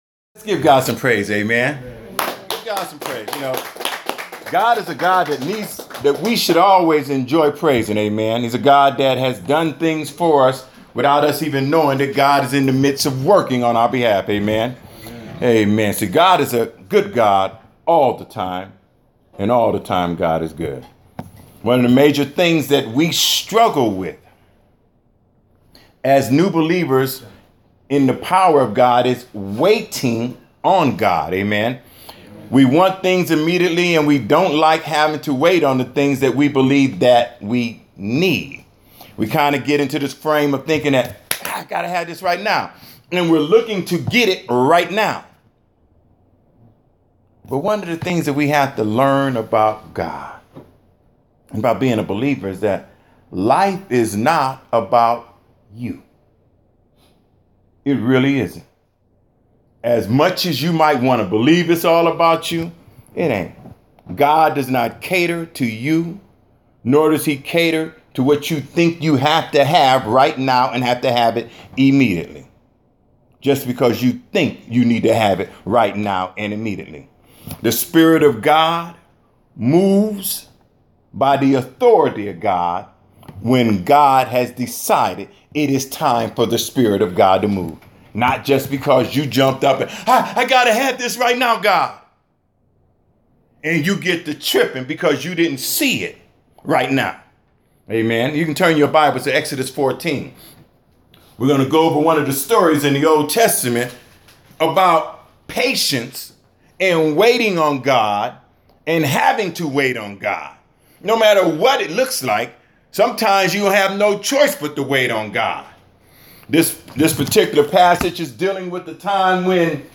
2016 Waiting On The Lord Preacher